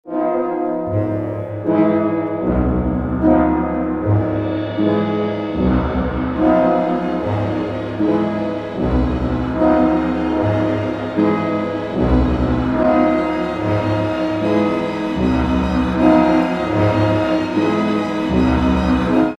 Trascrizione per orchestra di Maurice Ravel